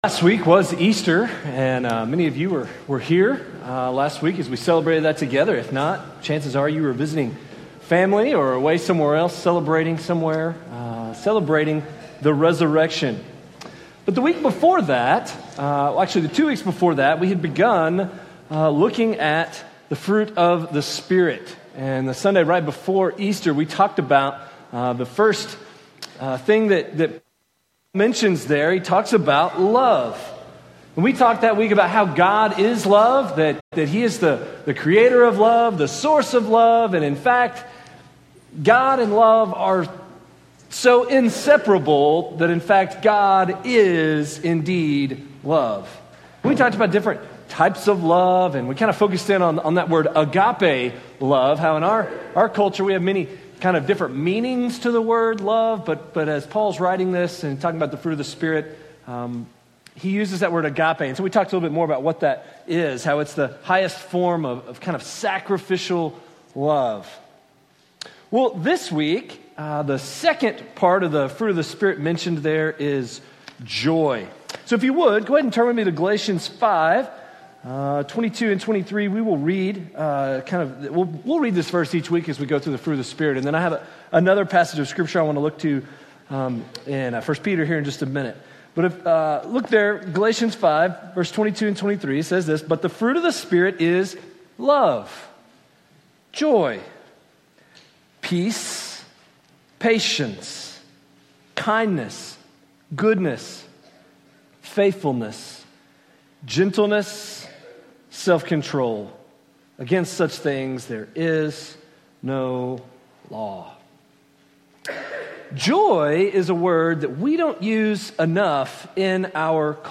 Sermons Archive - FBC Breckenridge